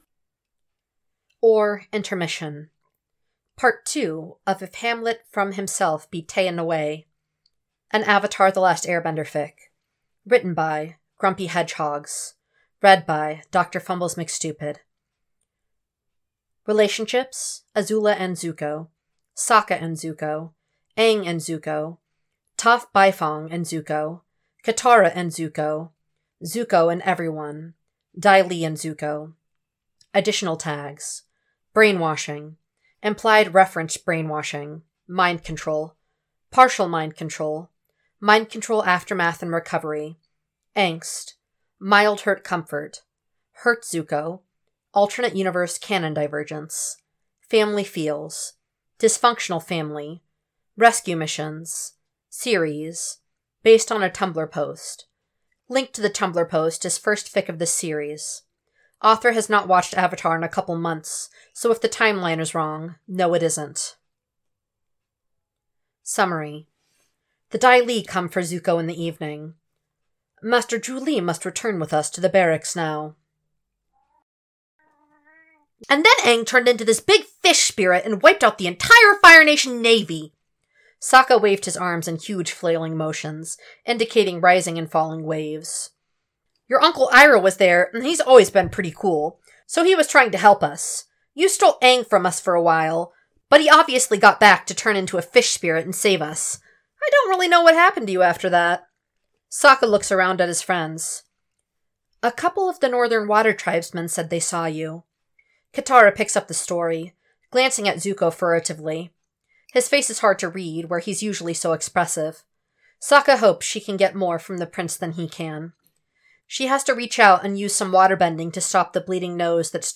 [Podfic] or